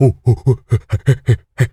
monkey_chatter_09.wav